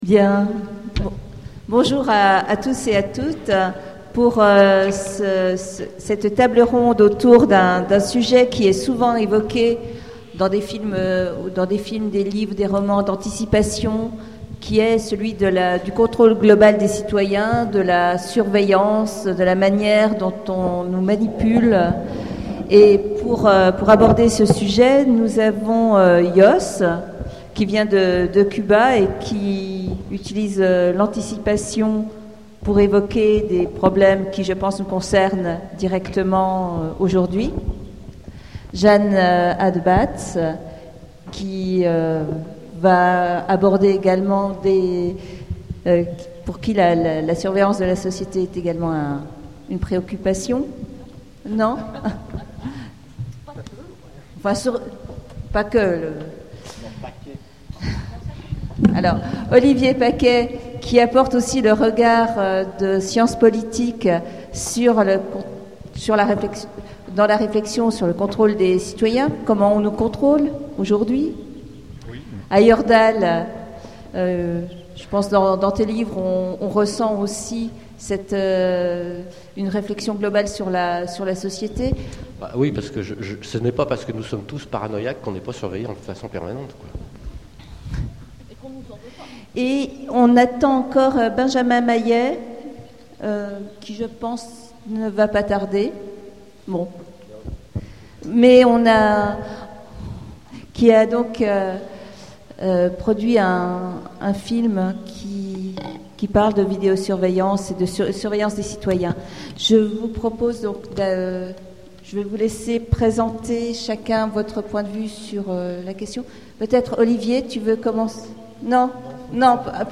Imaginales 2014 : Conférence Entre surveillance et paranoïa